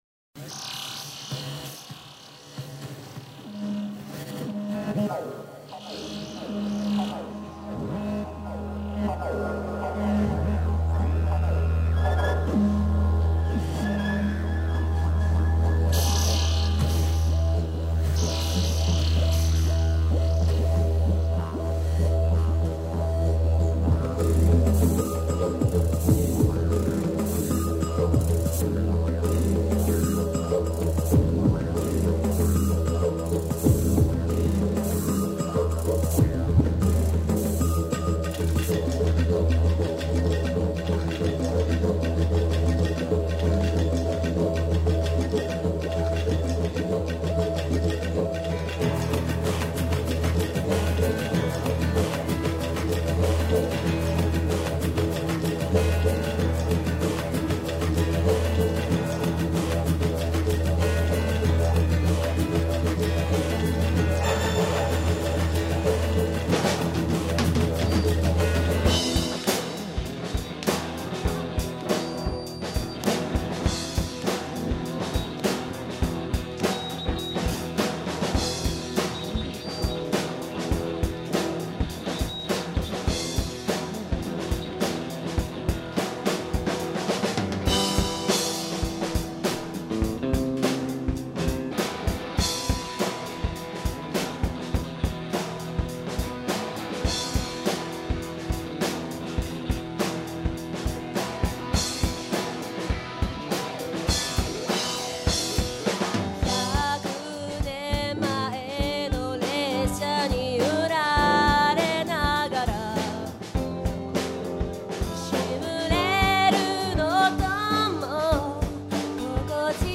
最初はSE流してたんですね。